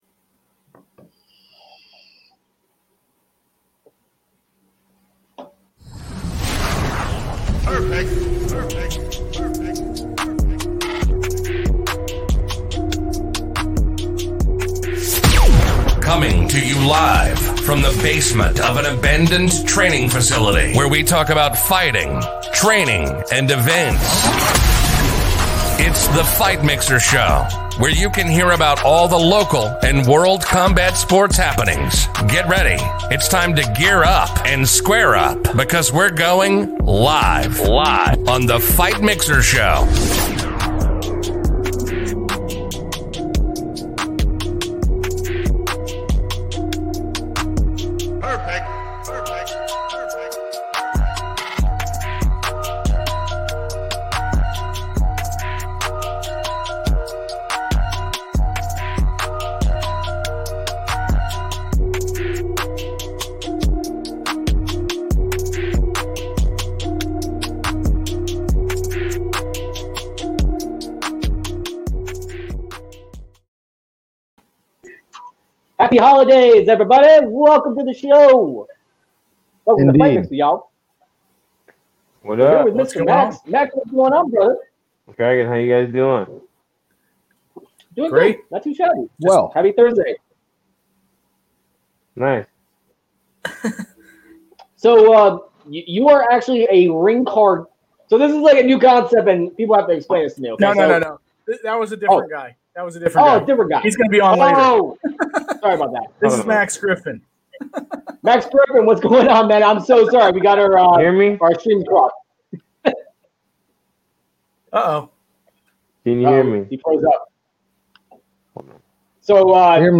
Max-_Pain_-Griffin-Interview.mp3